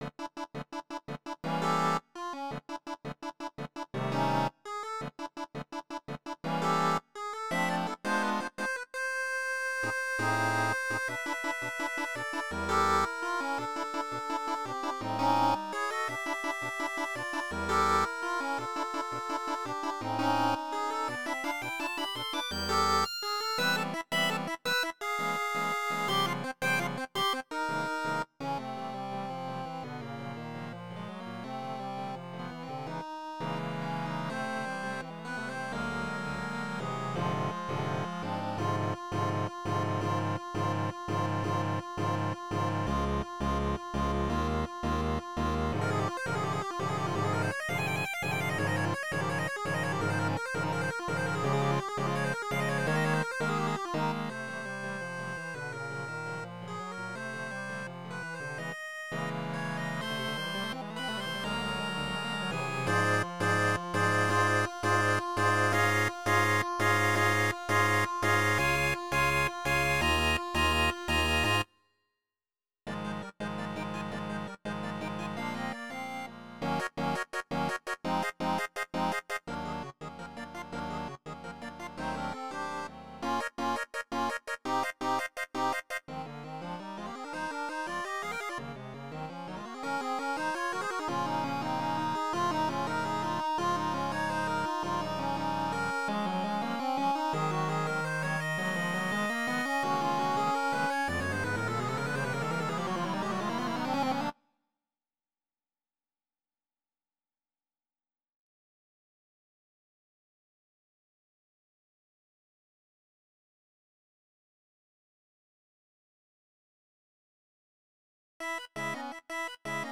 composizione per due fisarmoniche